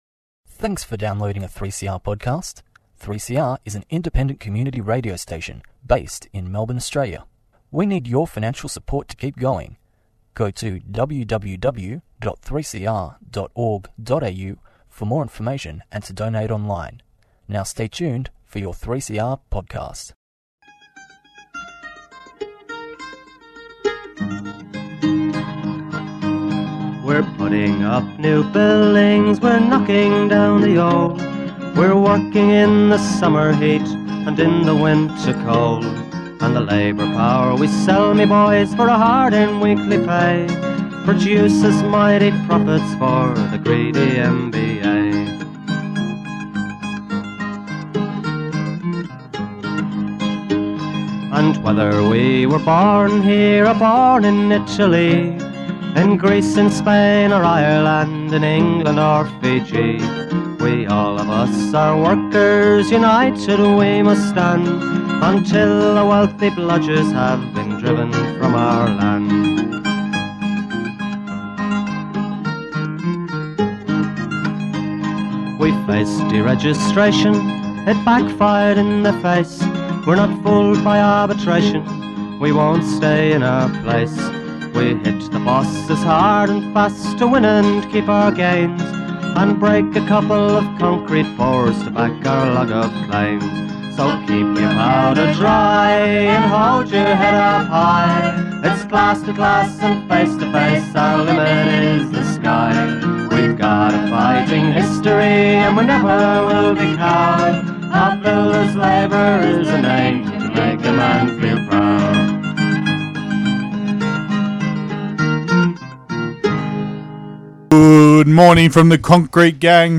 Weekly update from the CFMEU Construction & General (VIC/TAS). Westgate Bridge Memorial report and some very sorry news, both reminders that safety & mental health come first. Union and industry updates, and another poetry reading from the late Georgie Despard's originals, still as relevant today.